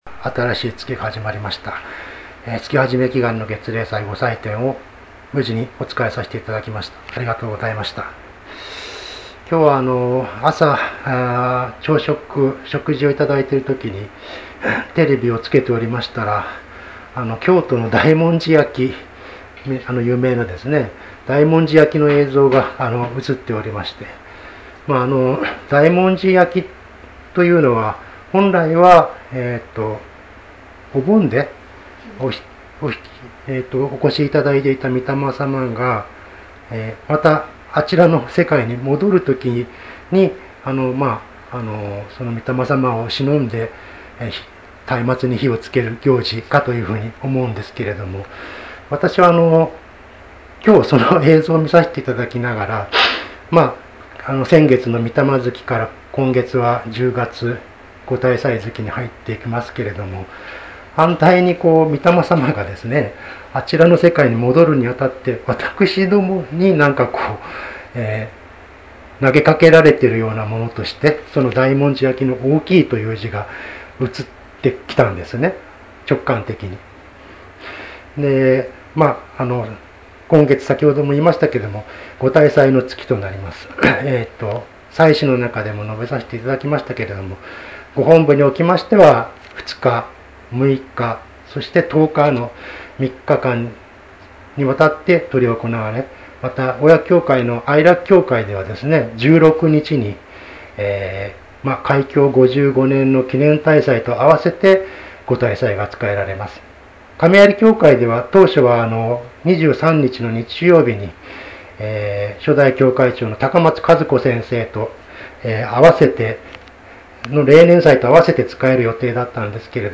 月例祭教話